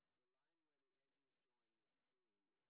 sp22_street_snr10.wav